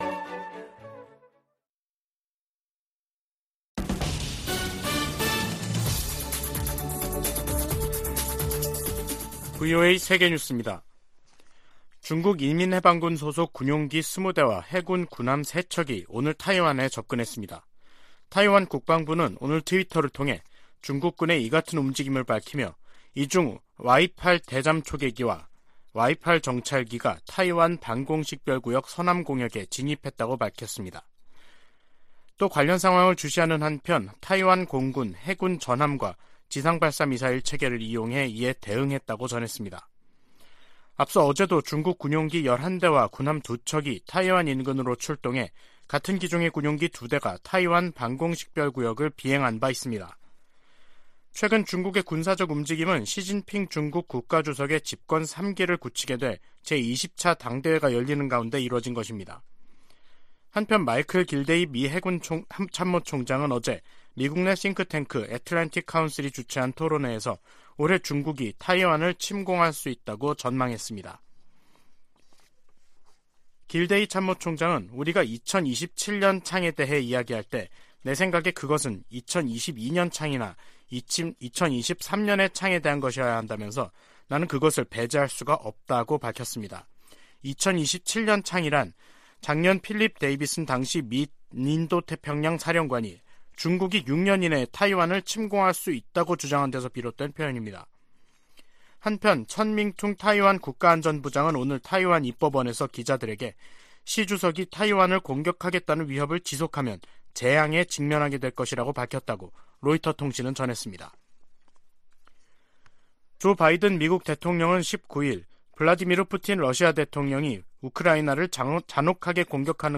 VOA 한국어 간판 뉴스 프로그램 '뉴스 투데이', 2022년 10월 20일 3부 방송입니다. 미 국무부는 연이은 북한 포 사격에 심각한 우려를 나타내며 한국과 일본에 악영향을 줄 수 있다고 지적했습니다. 미 공군 전략폭격기 B-1B가 괌에 전개됐다고 태평양공군사령부가 확인했습니다. 한국의 다연장 로켓 구매 계약을 체결한 폴란드는 러시아의 침공을 저지하기 위해 이 로켓이 필요하다고 설명했습니다.